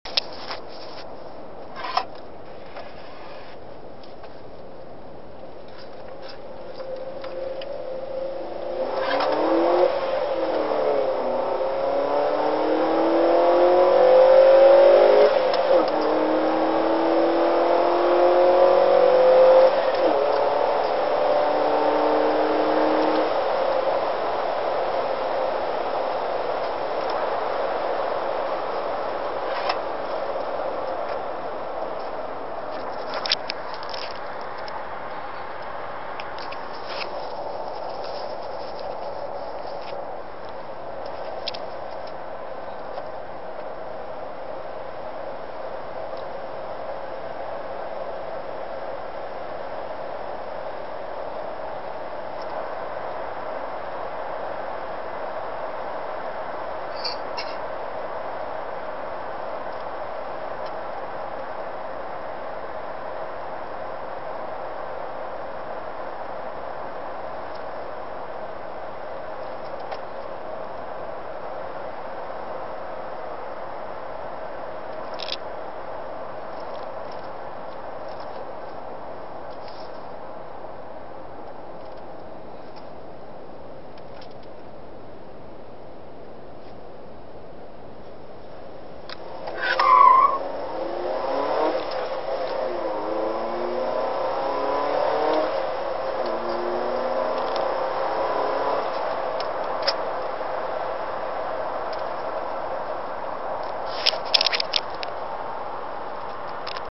What it sounds like with the header
Someone wanted to hear how my car sounded with header so here it goes. Thunder header, Fujita CAI. Yea i know i messed up on one of my shifts but w/e.